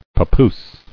[pa·poose]